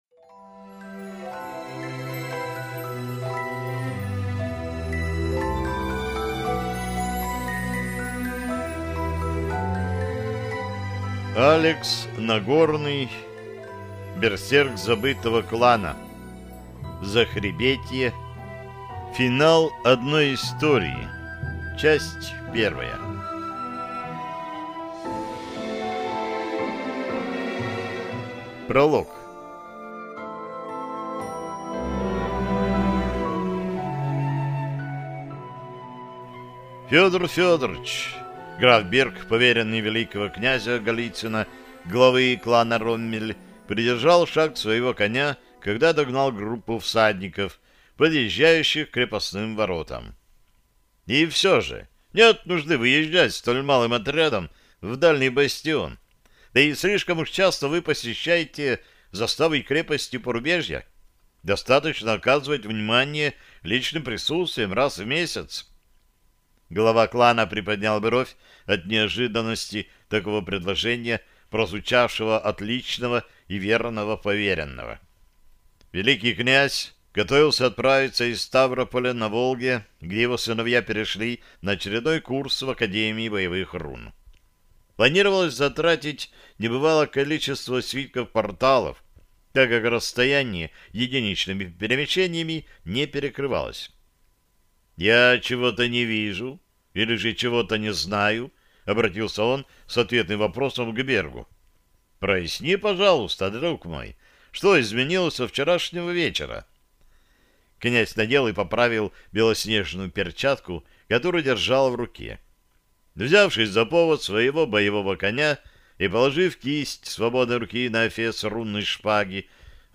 Аудиокнига Берсерк забытого клана. Рунические войны Захребетья | Библиотека аудиокниг